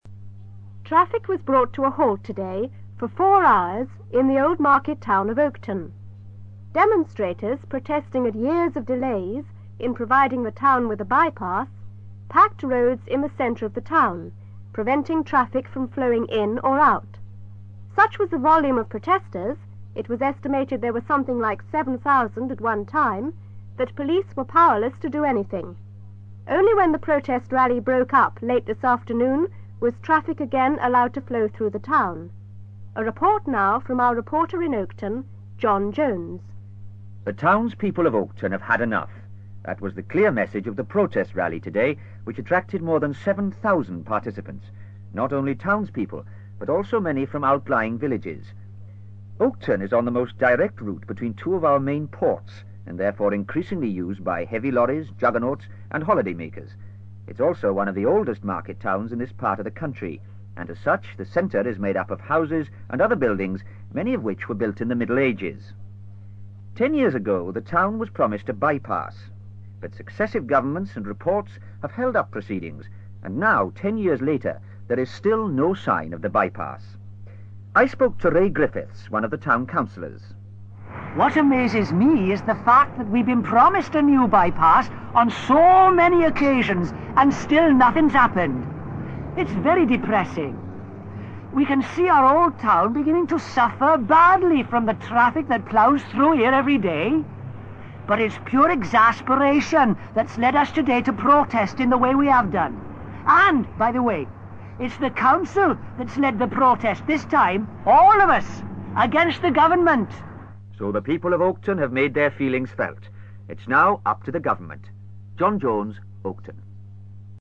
ACTIVITY 166: You are going to listen to a radio news item .